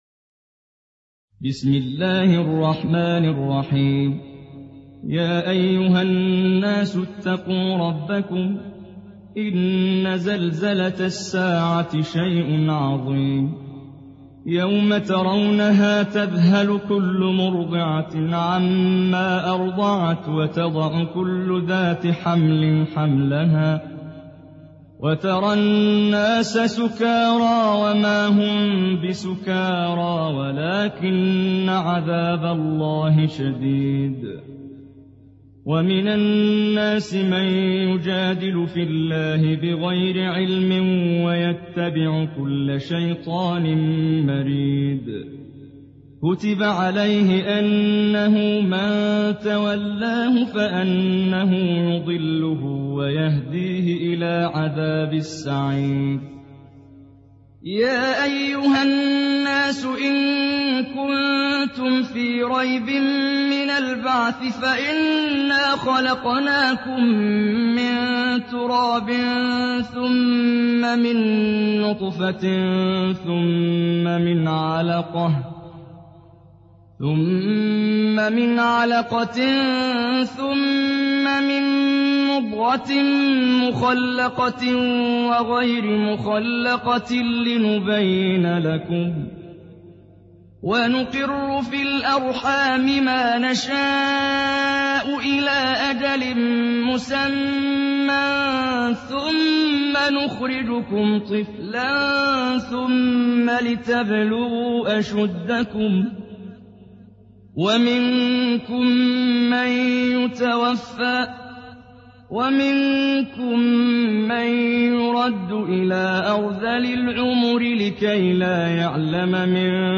Hac Suresi İndir mp3 Muhammad Jibreel Riwayat Hafs an Asim, Kurani indirin ve mp3 tam doğrudan bağlantılar dinle